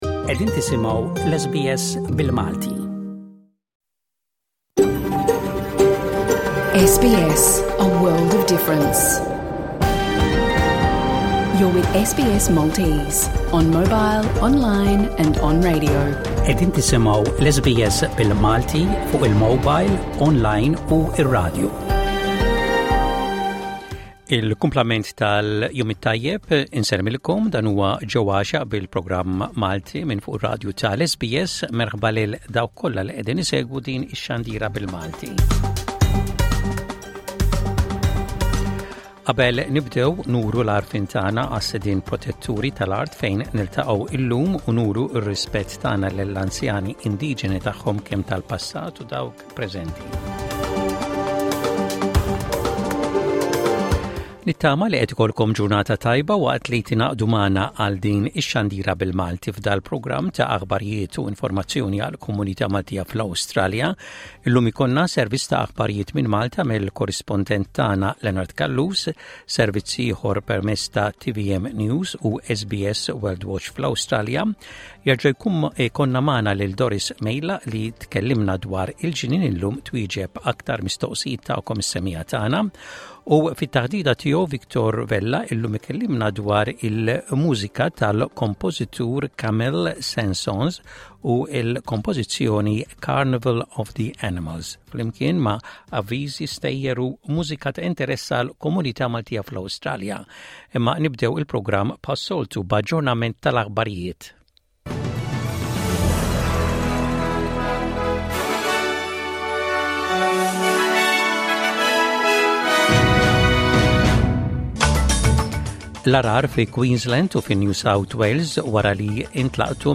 Jinkludi fost affarijiet oħra, l-aħbarijiet mill-Awstralja, servizz ta' aħbarijiet minn Malta mill-korrispondent